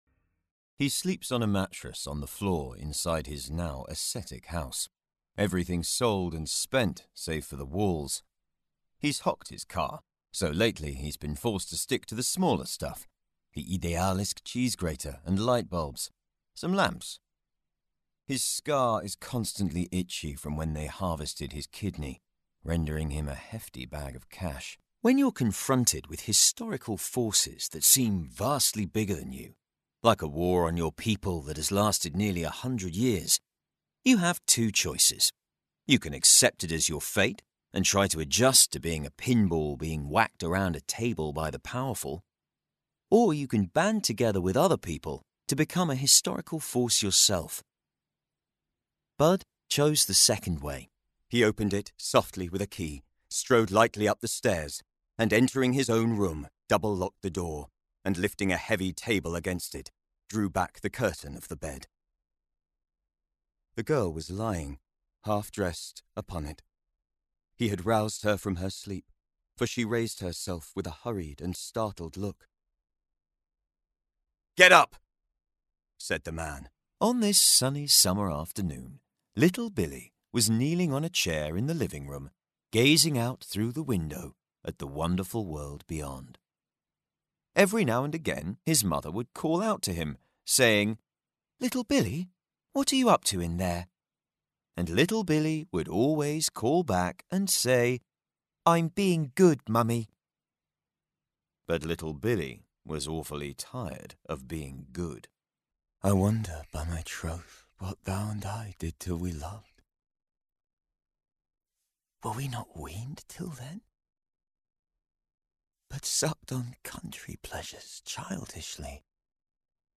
Livres audio
Microphone à valve à condensateur cardioïde Sontronics Aria
Microphone dynamique SHURE SM7B